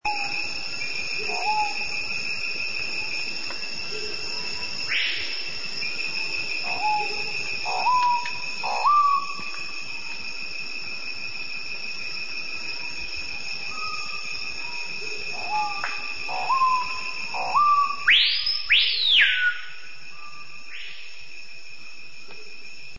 screamingpiha.mp3